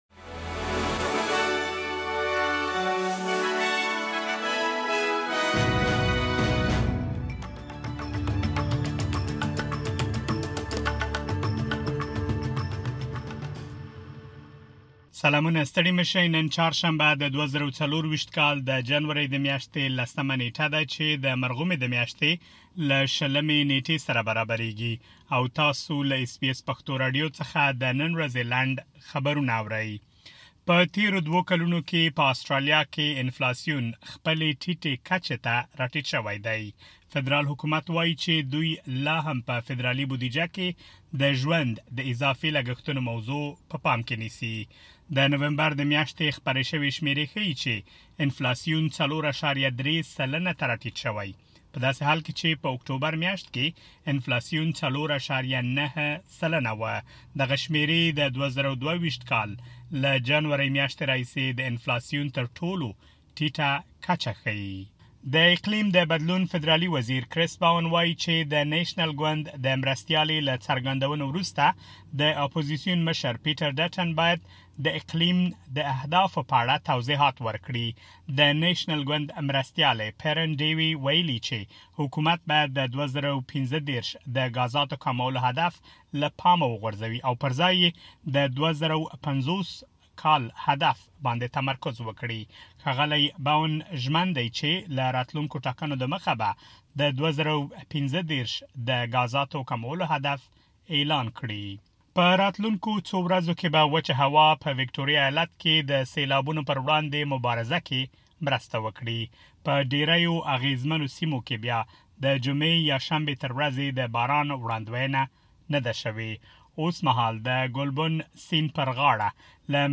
د اس بي اس پښتو راډیو د نن ورځې لنډ خبرونه | ۱۰ جنوري ۲۰۲۴